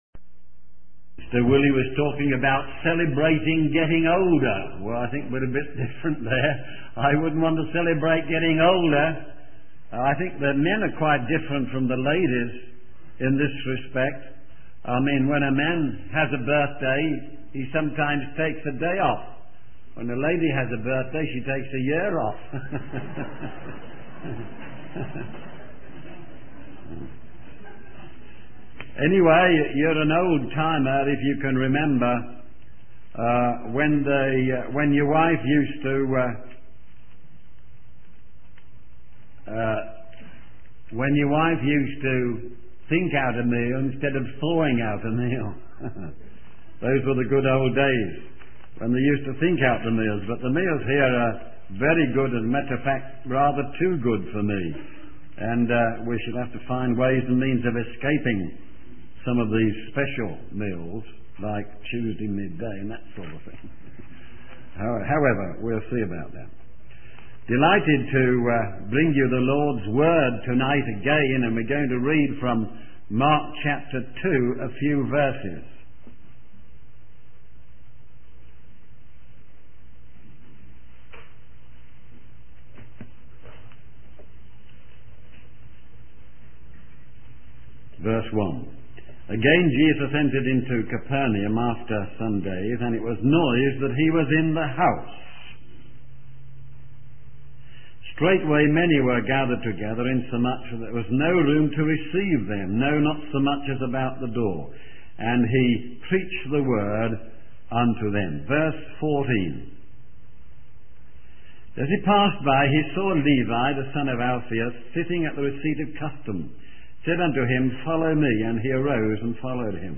In this sermon, the speaker begins by acknowledging a lack of liberty and energy in the previous session. He then provides a recap of the Gospel according to Mark, explaining that it was written by John Mark.